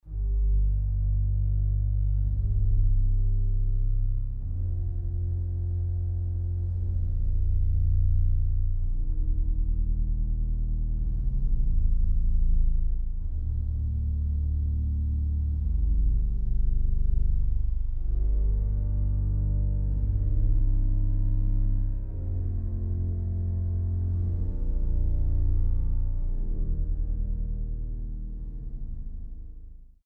Kaps-Orgel in Mariä Himmelfahrt zu Dachau